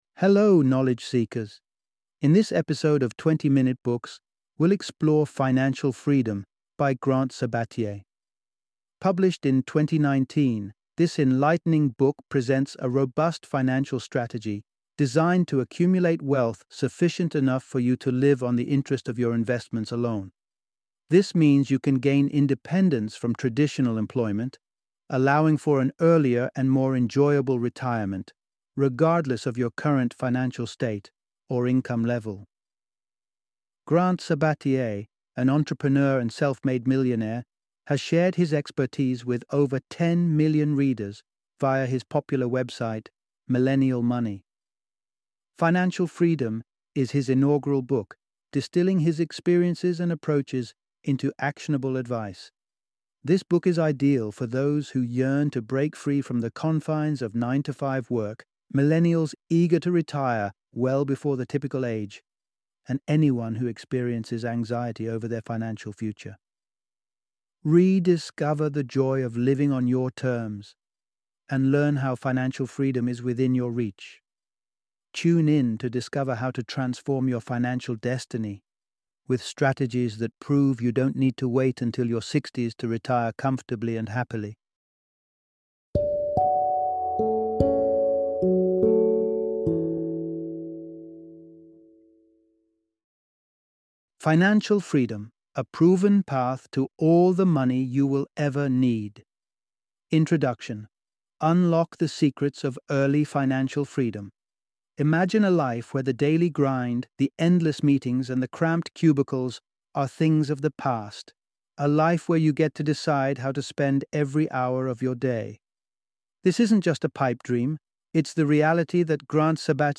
Financial Freedom - Audiobook Summary